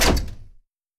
door-open.wav